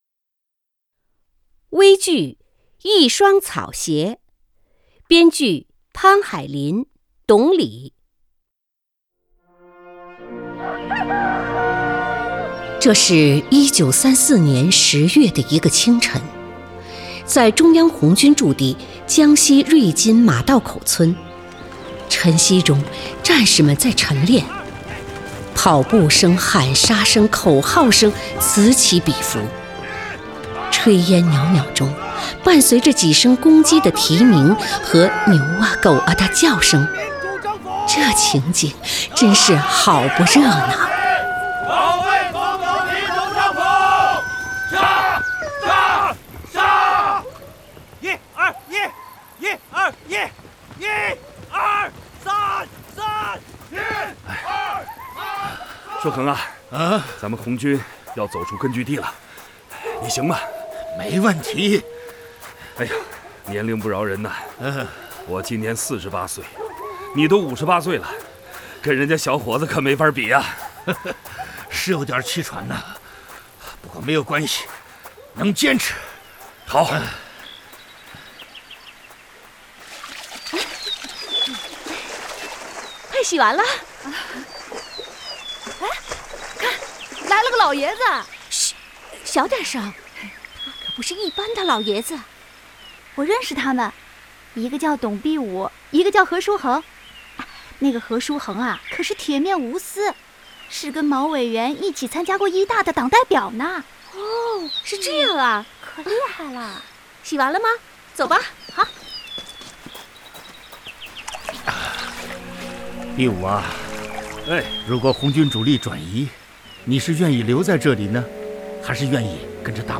• 广播类型：微广播剧